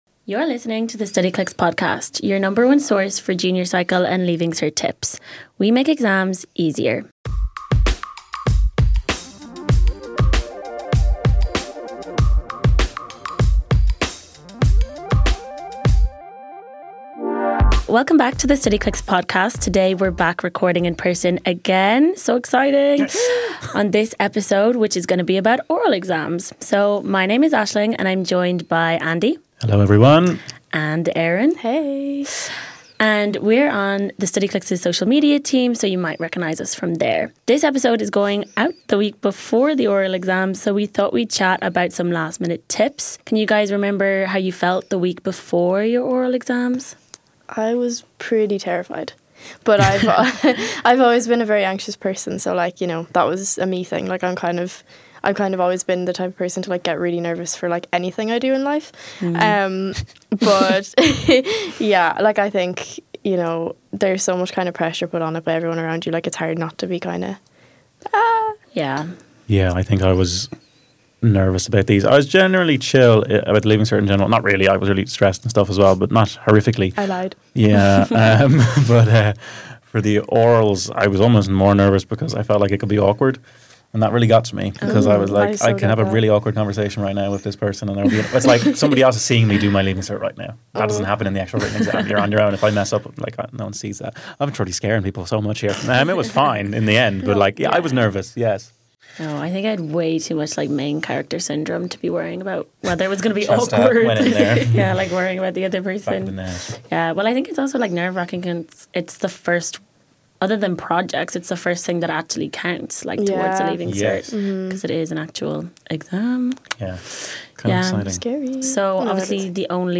chat through some of things you can do to prepare, even when time is running out.